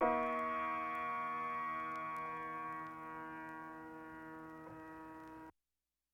Elongated Note.wav